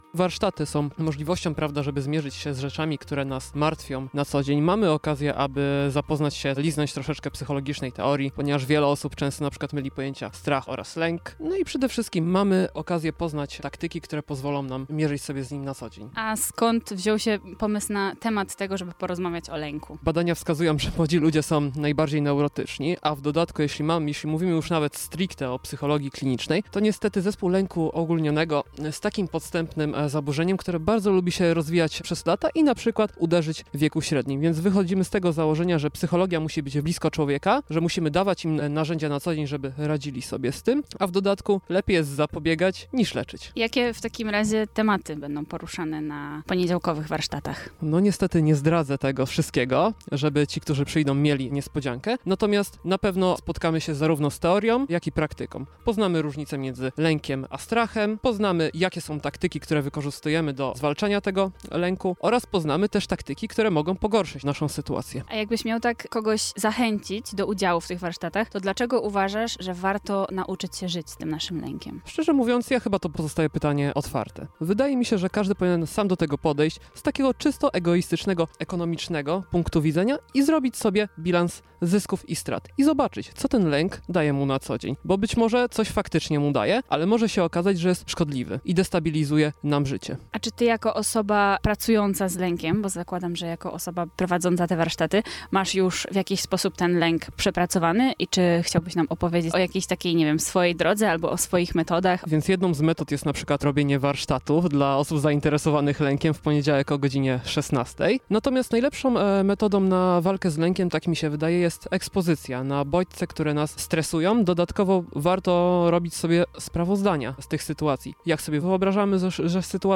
Lęk czy strach? O radzeniu sobie z emocjami - posłuchaj rozmowy